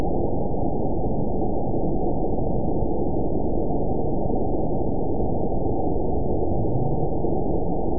event 912634 date 03/30/22 time 21:58:11 GMT (3 years, 1 month ago) score 8.97 location TSS-AB02 detected by nrw target species NRW annotations +NRW Spectrogram: Frequency (kHz) vs. Time (s) audio not available .wav